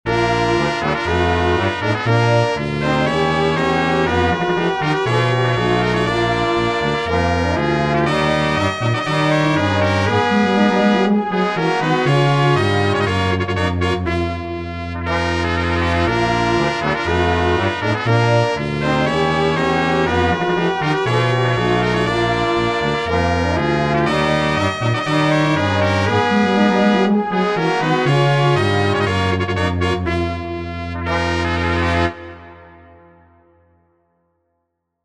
Short 120bpm loop in 18edo
18edo_demo.mp3